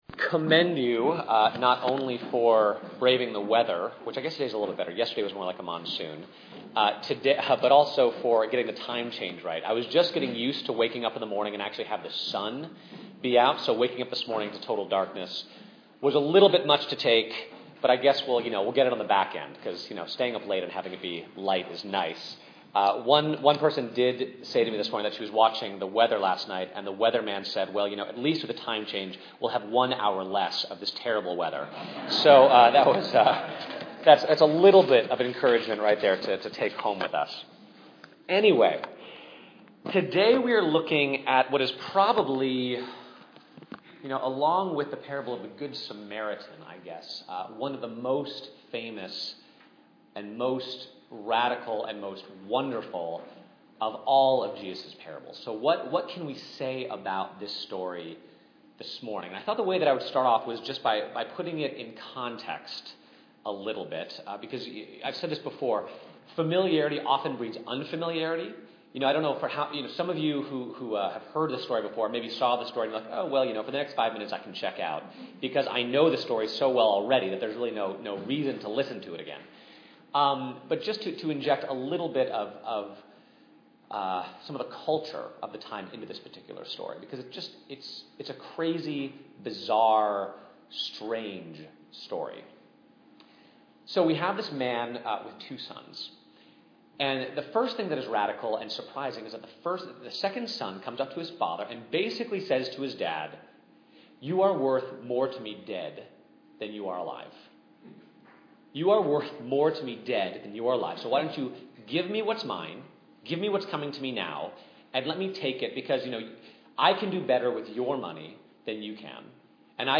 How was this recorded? Venue: St. Paul's Church NYC Scripture: Luke 15:11-32